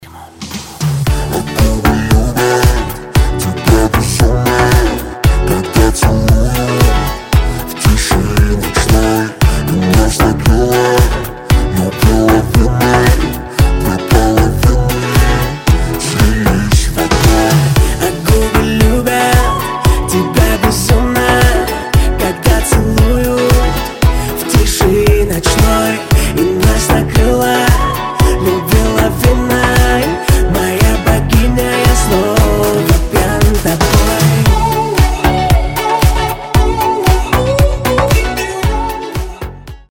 поп
мужской вокал
dance
vocal